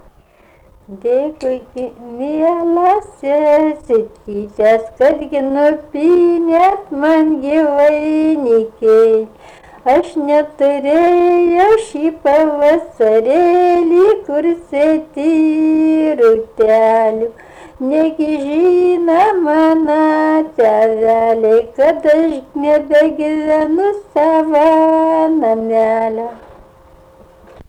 rauda